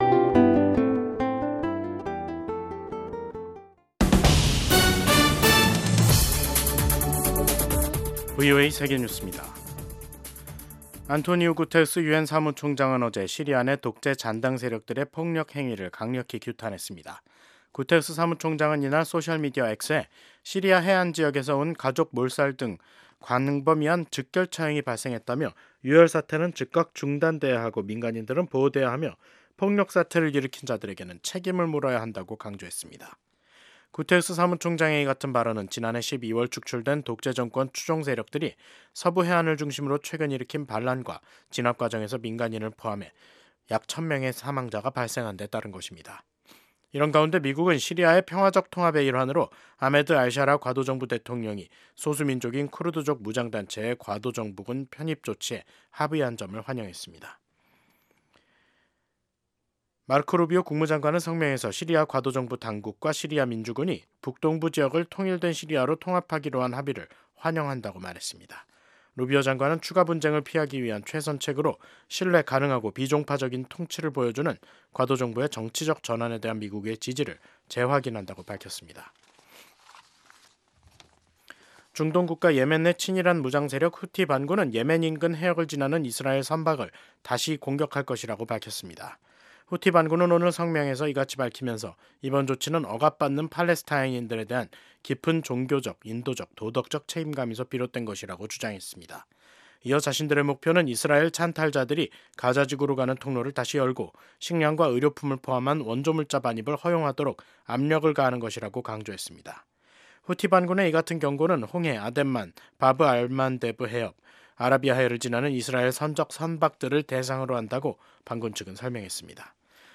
VOA 한국어 간판 뉴스 프로그램 '뉴스 투데이', 2025년 3월 12일 3부 방송입니다. 북한의 우크라이나전 개입 중단이 도널드 트럼프 미국 행정부의 요구라고 조셉 윤 한국 주재 미국 대사대리가 밝혔습니다. 미국 정부가 북한이 도널드 트럼프 대통령 취임 이후 처음으로 탄도미사일을 발사한 것을 강력히 규탄했습니다.